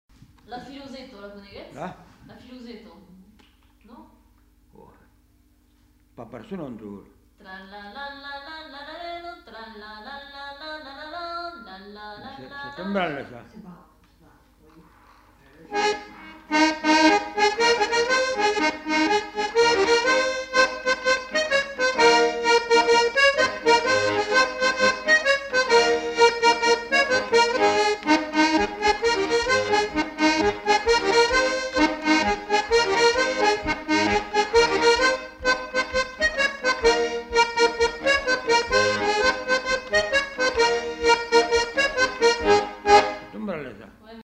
Aire culturelle : Savès
Lieu : Beaumont-de-Lomagne
Genre : morceau instrumental
Instrument de musique : accordéon diatonique
Danse : branle de Lomagne